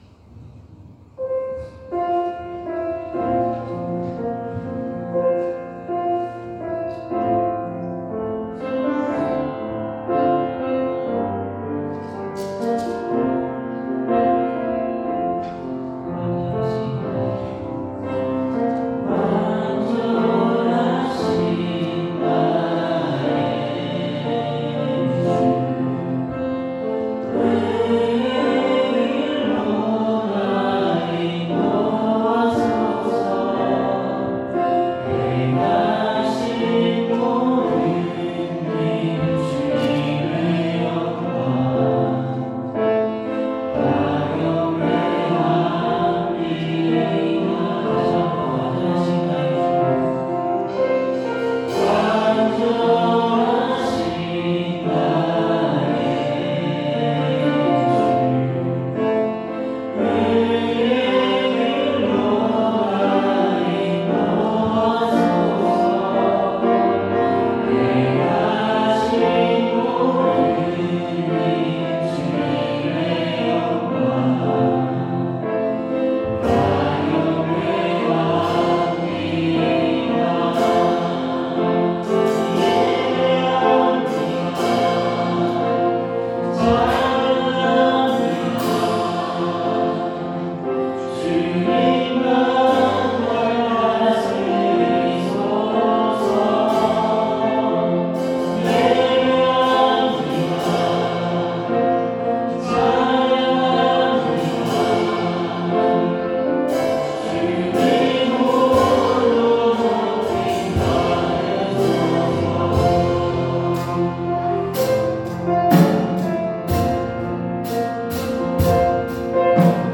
2026년 02월 01일 주일찬양